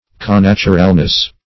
Search Result for " connaturalness" : The Collaborative International Dictionary of English v.0.48: Connaturalness \Con*nat"u*ral*ness\, n. Participation of the same nature; natural union.
connaturalness.mp3